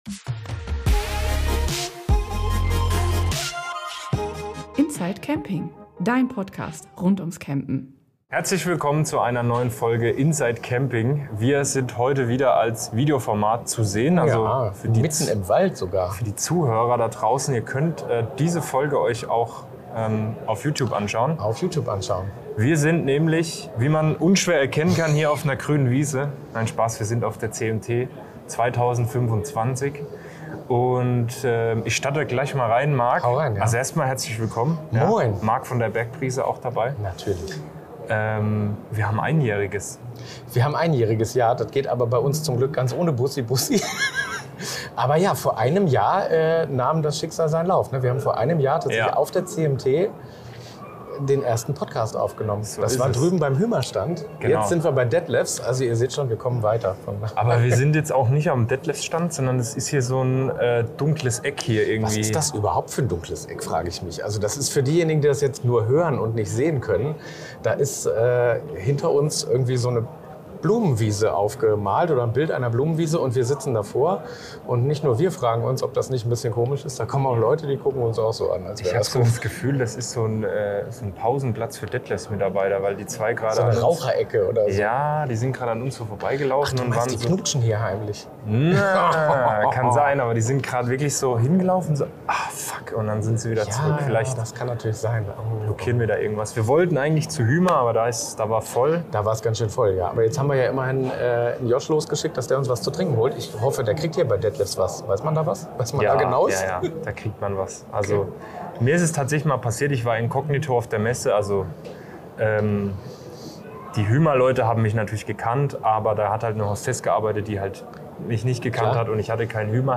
Uns gibt’s jetzt schon ein Jahr und das wird gefeiert – wo geht das besser als auf der CMT 2025 in Stuttgart?